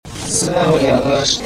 このリバース・スピーチは表音依存の傾向が見られるものの、 橋下氏が語気を強めた個所で現れており、日本維新の会が新たに生まれ変わったことを 強調していた点においても、表と裏が密接に関係した好例の一つである。